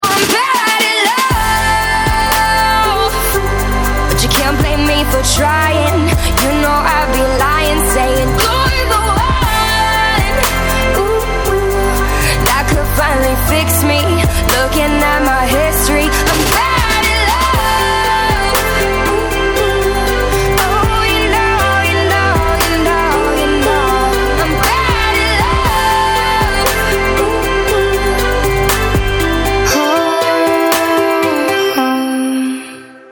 • Качество: 128, Stereo
поп
громкие
женский вокал
Indie